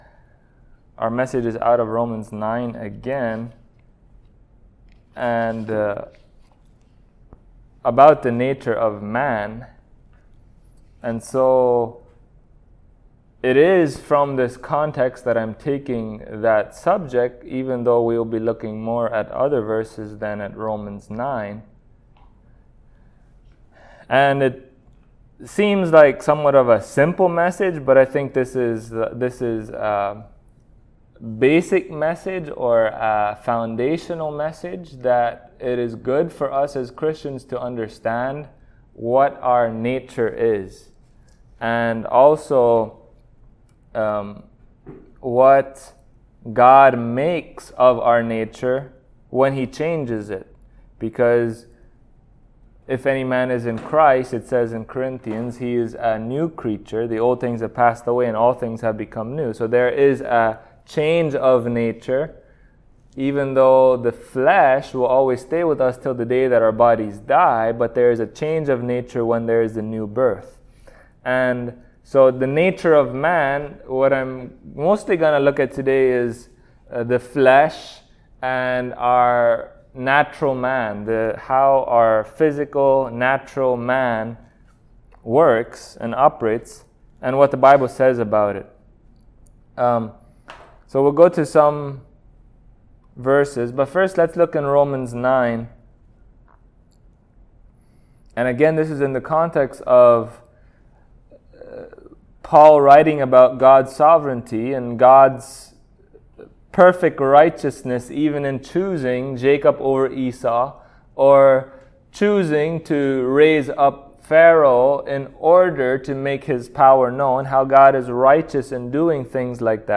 Romans 9:20 Service Type: Sunday Morning Topics: Flesh , Nature of Man , Sin « Zeal and Ignorance Sanctification